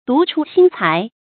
注音：ㄉㄨˊ ㄔㄨ ㄒㄧㄣ ㄘㄞˊ
獨出心裁的讀法